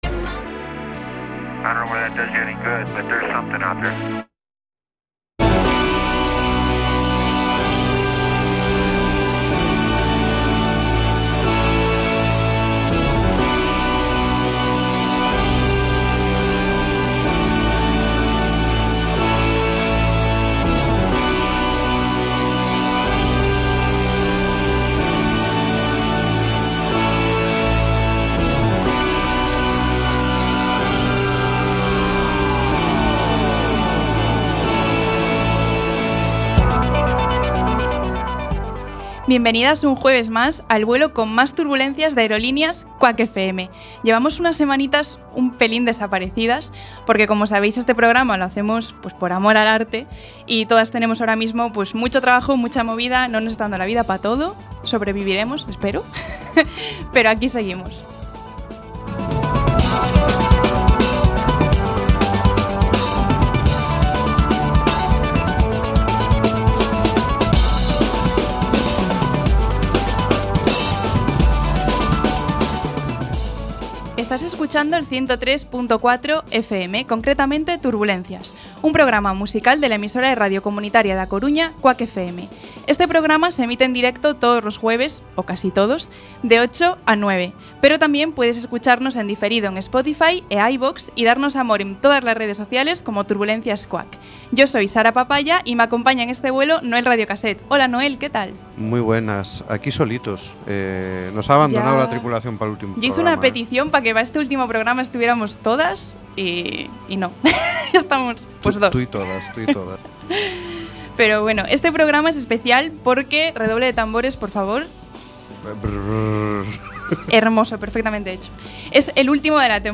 Turbulencias es un Magazine musical que abarca la actualidad musical a través de noticias, entrevistas, agenda y monográficos además de otras muchas secciones. Queremos que descubras música a nuestro lado.